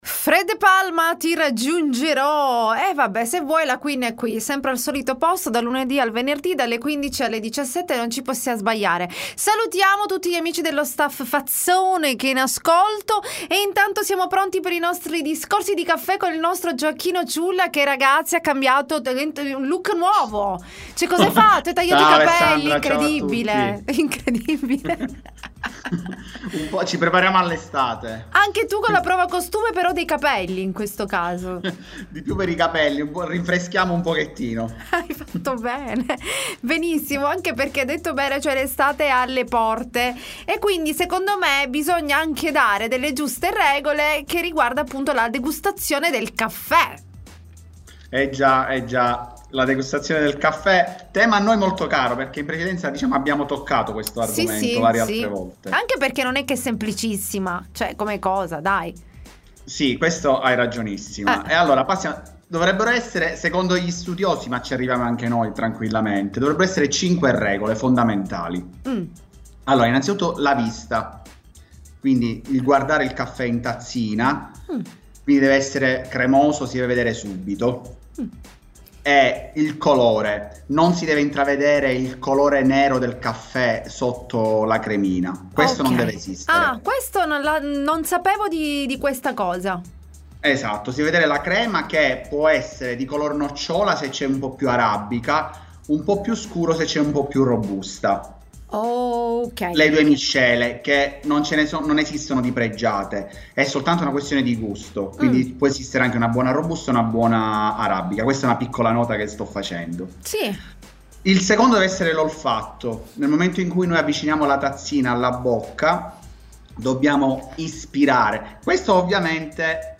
L.T. Intervista I Discorsi di Caffè